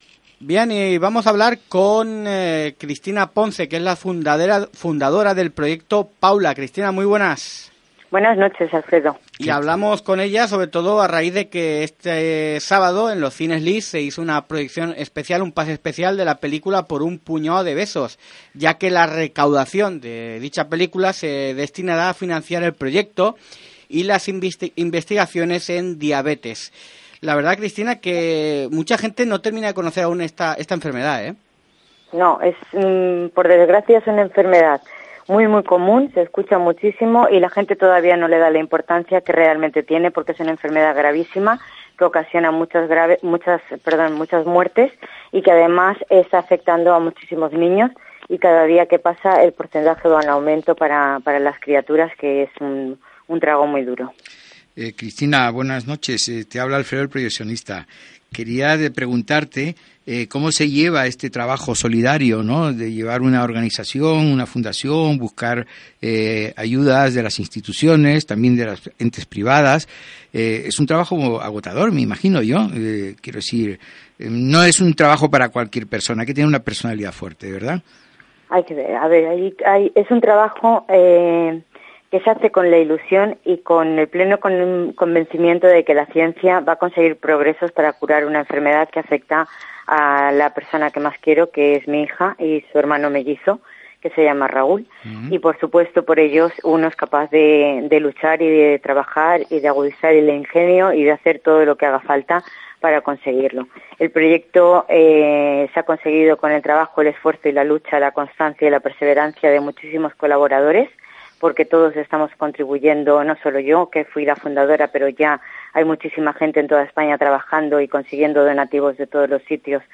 Entrevista
Radio.97.7.mp3